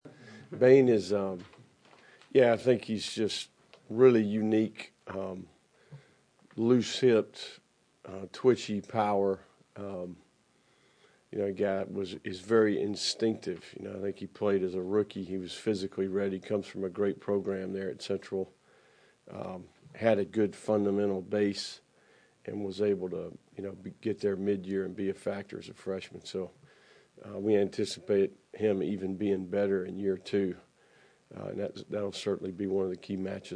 Florida football coach Billy Napier previewed the Week 1 matchup against No. 19 Miami during a news conference Monday.